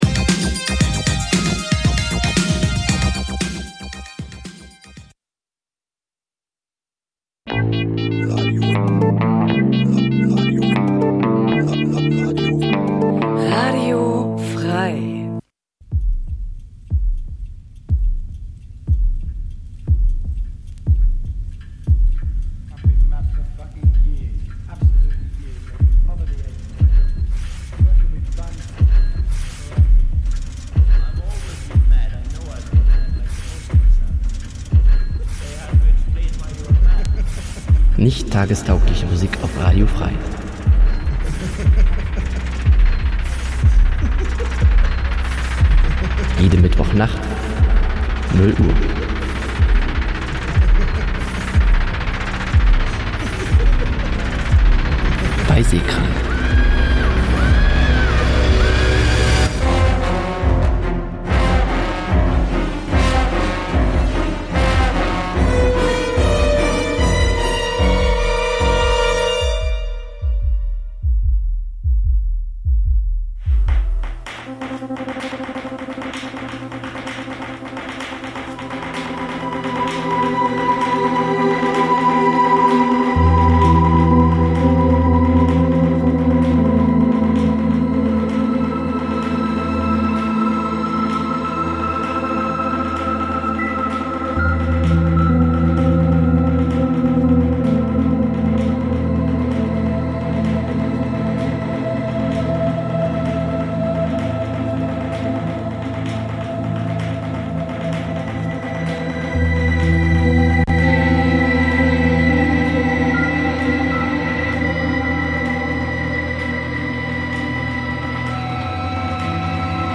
SEHKRANK pr�sentiert Musik auch jenseits dieser alternativen H�rgewohnheiten, mal als Album, mal als Mix.